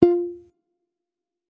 receive-message.mp3